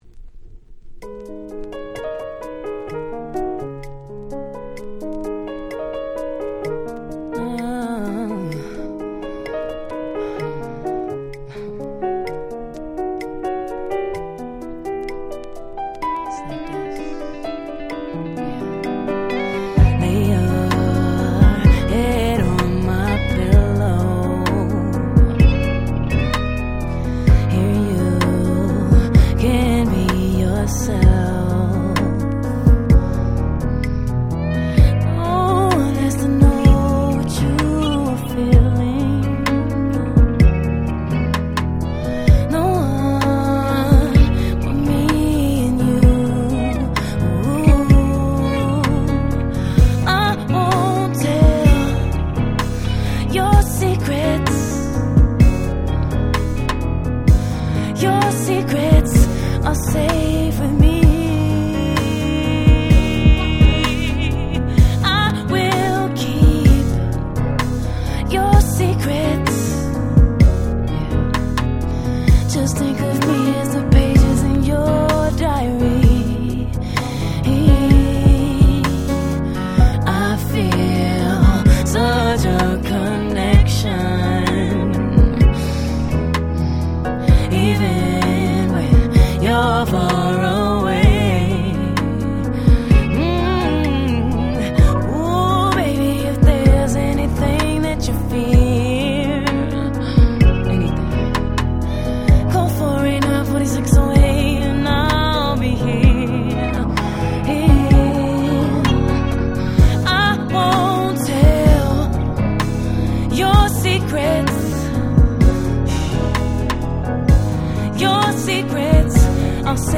04' Super Hit R&B / Slow Jam !!
スロウジャム バラード